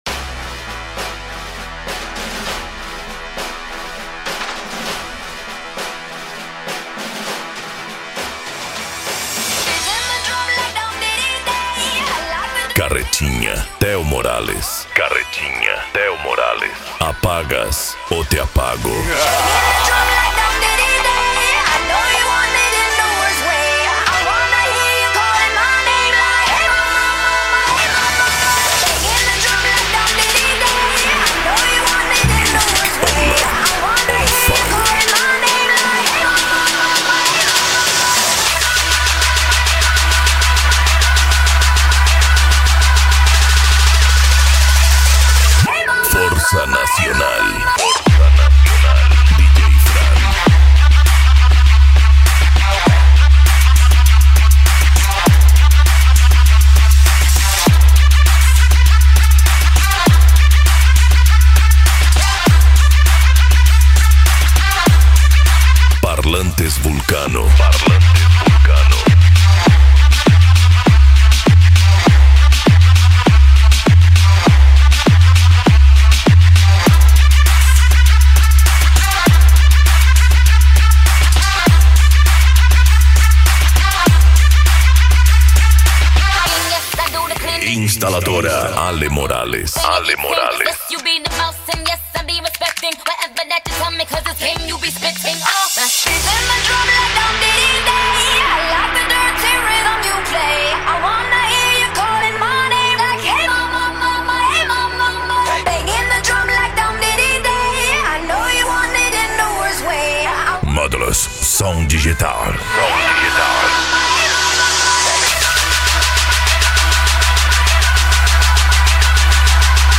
Psy Trance
Remix